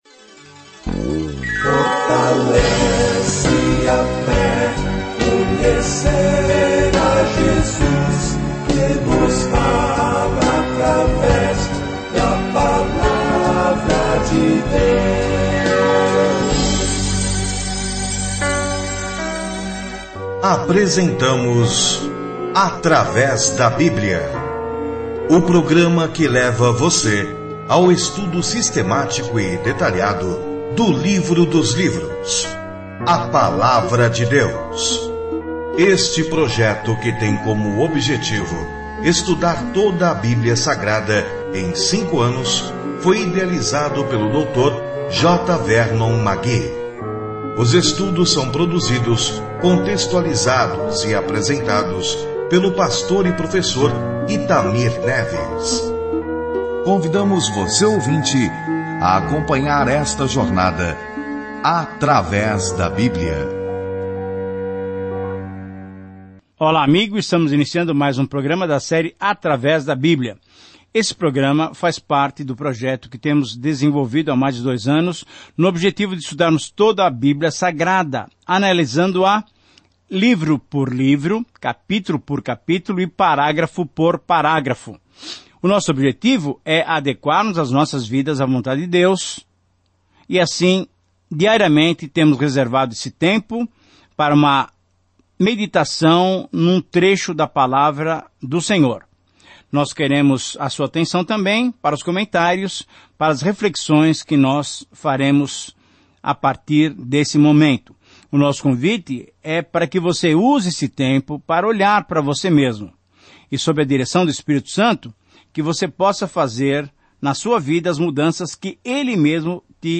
Scripture Ephesians 1:1-2 Day 2 Start this Plan Day 4 About this Plan Das belas alturas do que Deus deseja para seus filhos, a carta aos Efésios explica como andar na graça, na paz e no amor de Deus. Viaje diariamente por Efésios enquanto ouve o estudo em áudio e lê versículos selecionados da palavra de Deus.